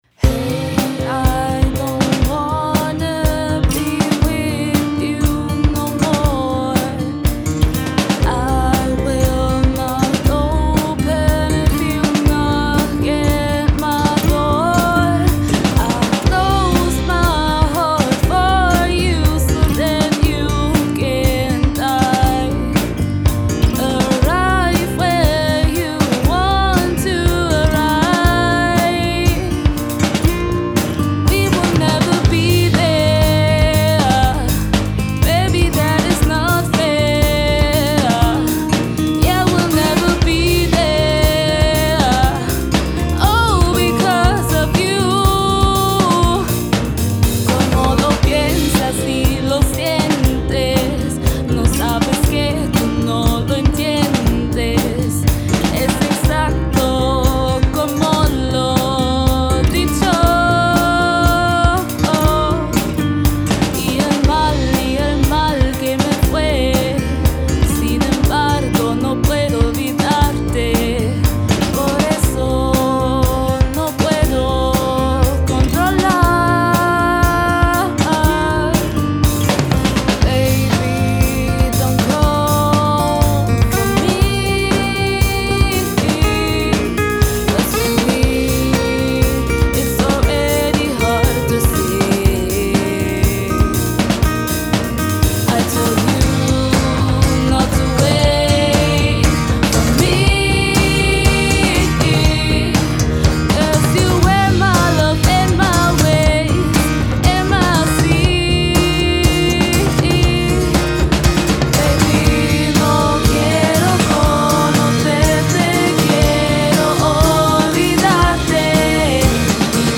Wie ein Chamäleon passen wir uns den aktuellen Gegebenheiten an und haben von März bis Mai eine virtuelle Jamsession organisiert.
Im Anschluss haben wir das Ergebnis professionell vertont und ein Foto der Jammer:in erstellt.
Schlagzeug
Akustikgitarre
Bass
Gesang B